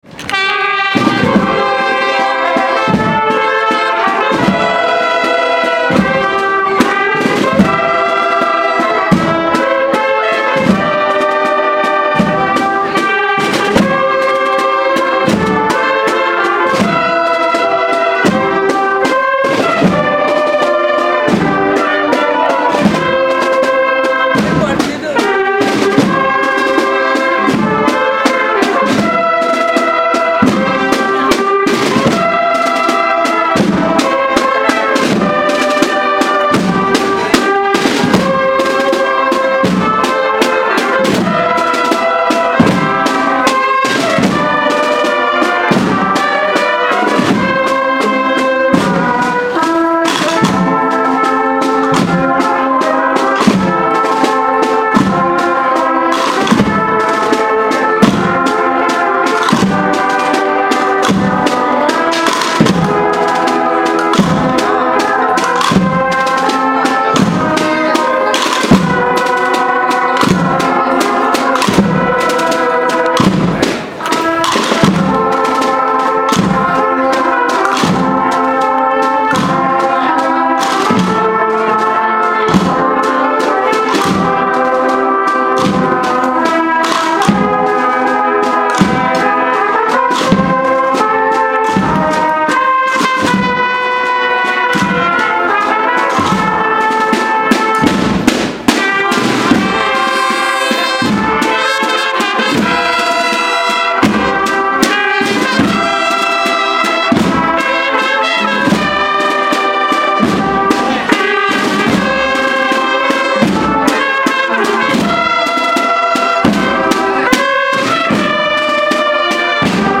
Numerosas personas participaron en la misa de campaña, reparto de roscones y posterior procesión con la imagen de San Francisco de Asís, que recorrió las inmediaciones del barrio, y en la que estuvieron presentes autoridades municipales.
La Banda de Corneta y Tambores de la Hermandad de la Verónica participó en la procesión.